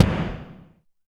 84 KICK   -R.wav